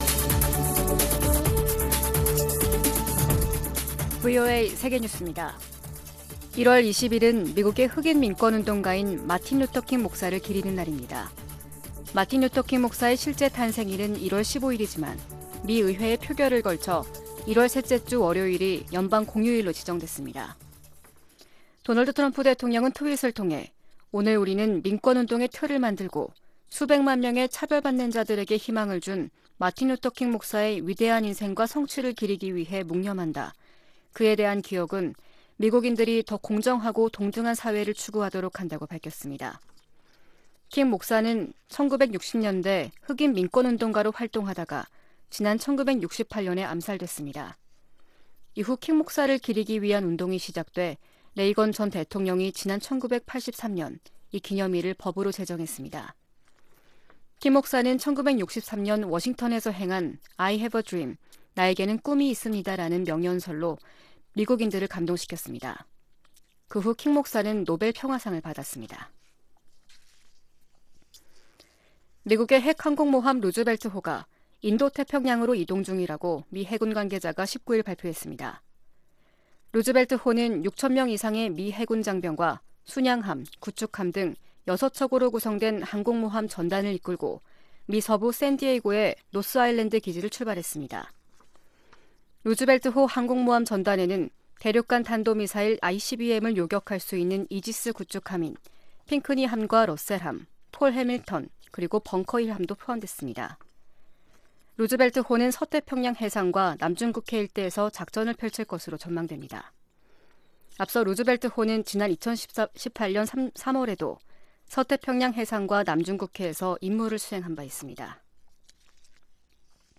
VOA 한국어 아침 뉴스 프로그램 '워싱턴 뉴스 광장' 2020년 1월 21일 방송입니다. 미국은 북한의 비핵화를 진전시키기 위한 한국 정부의 기여를 환영한다고 모건 오테이거스 국무부 대변인이 밝혔습니다. 미국의 전직 주한 대사들은 미국의 대사가 미국의 입장을 대변하는 것은 특별한 일이 아니라고 말했습니다. 북한의 미사일 역량이 매우 빠르게 고도화되고 있다고 미 합동참모부 차장이 밝혔습니다.